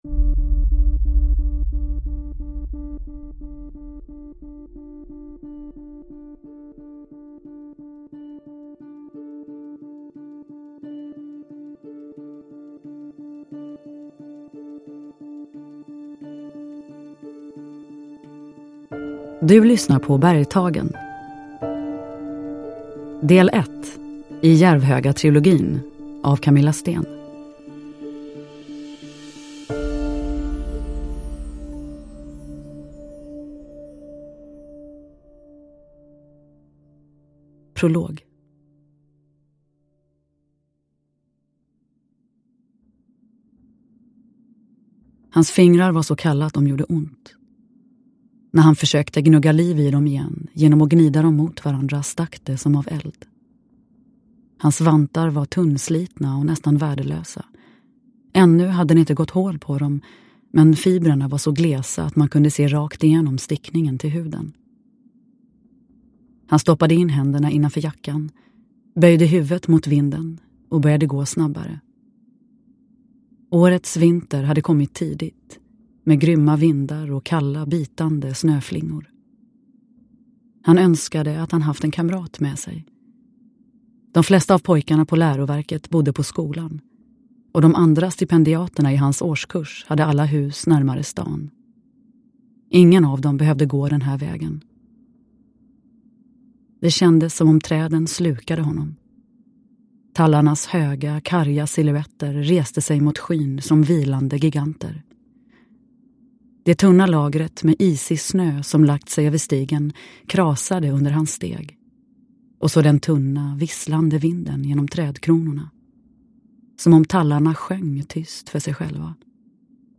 Uppläsare: Nina Zanjani